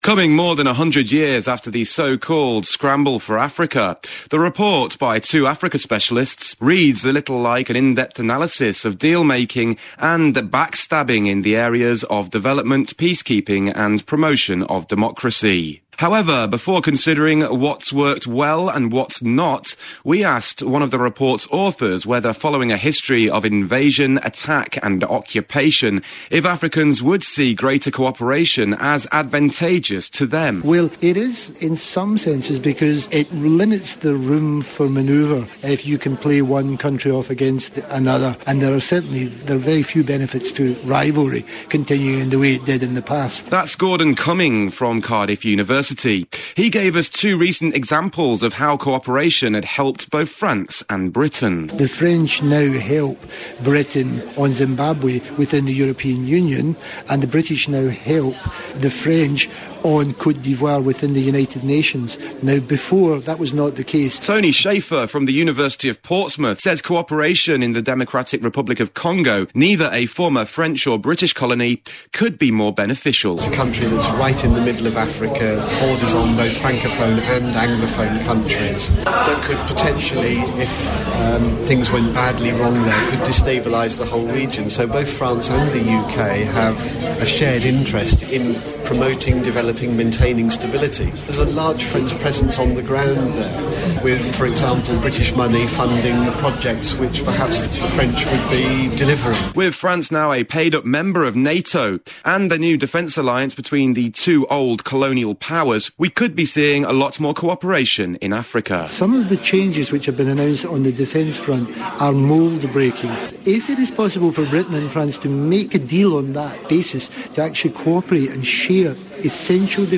Radio Report: Africa Report